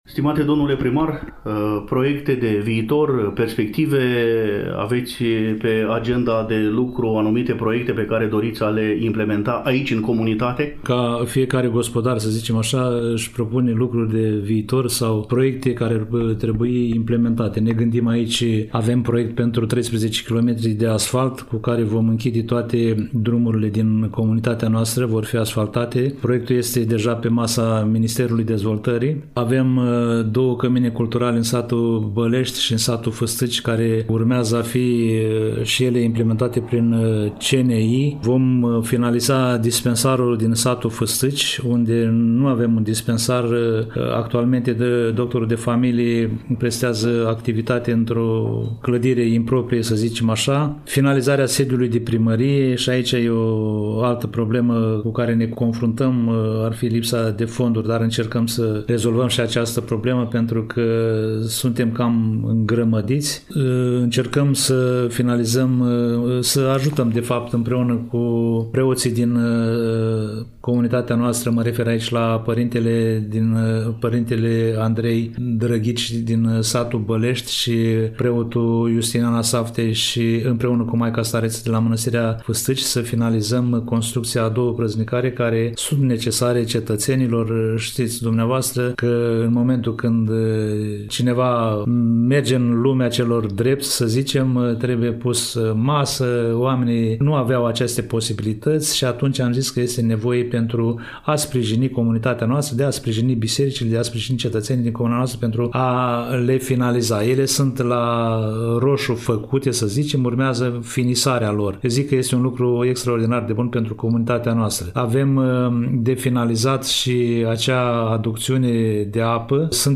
Primul interlocutor al ediției este domnul Hristache Sima, edilul șef al comunei.